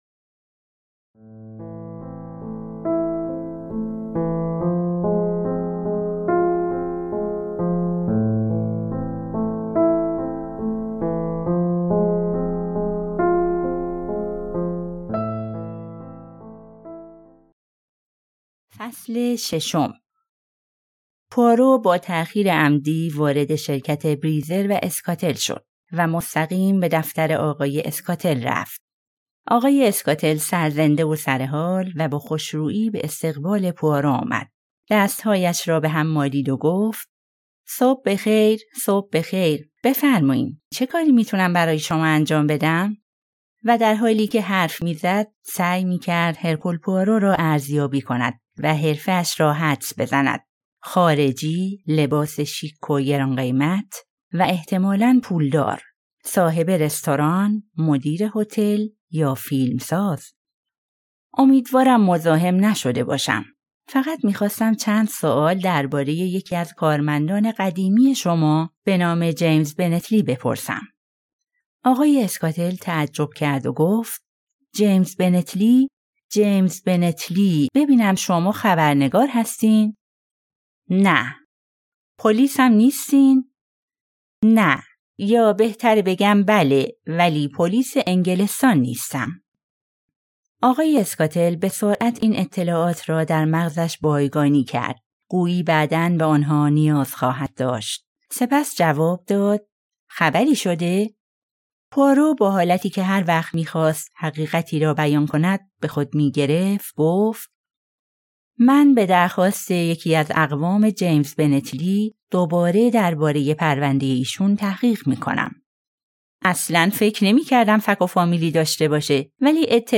قسمت ششم از کتاب صوتی جنایی قتل خانم مک گینتی منتشر شد.